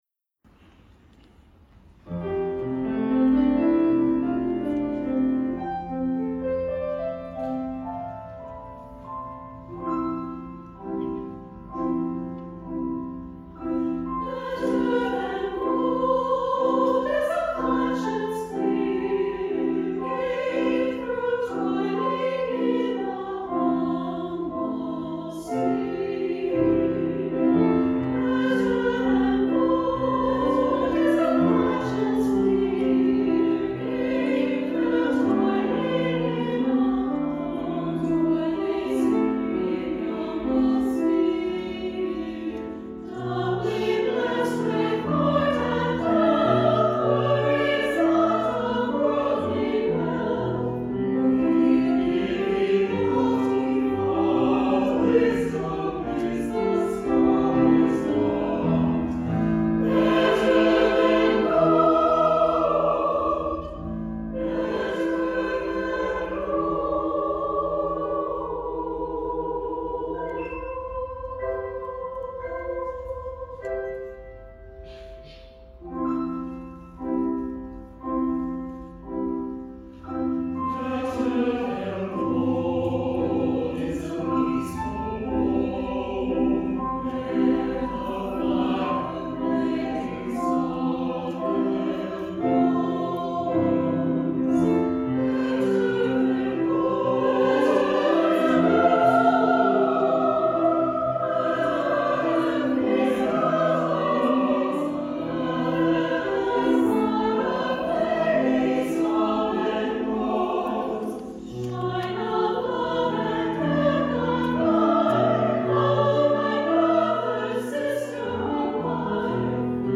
SSAB + Piano
This choral work
SSAB, Piano